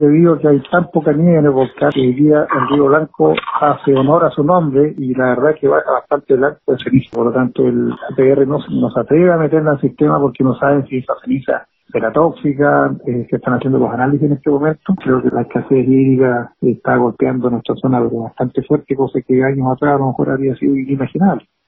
El alcalde de Puerto Octay, Gerardo Gunckel, dijo que el problema es más profundo, dado que la nieve cordillerana andina se derritió arrastrando ceniza volcánica.